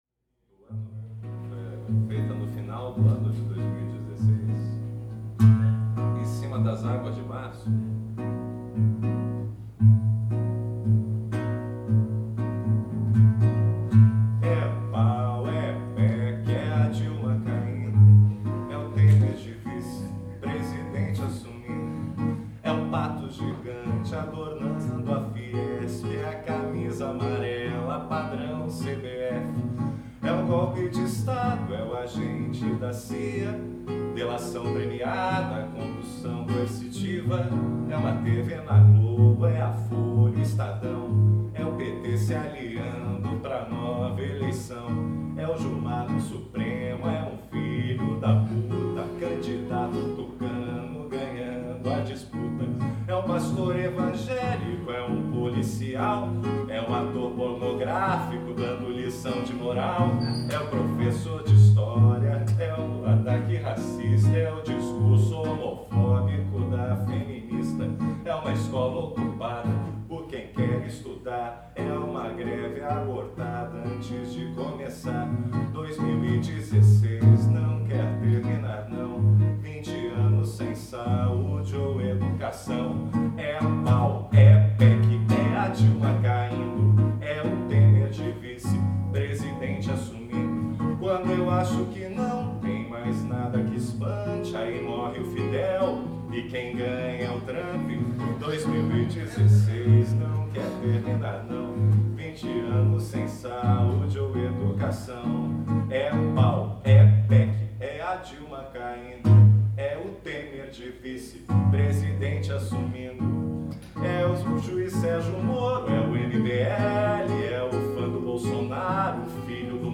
Paródia 08